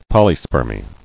[pol·y·sper·my]